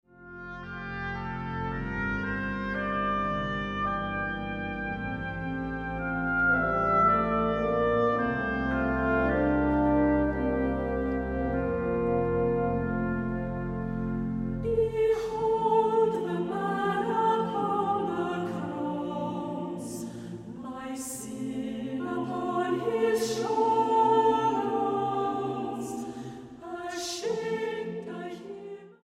STYLE: Hymnody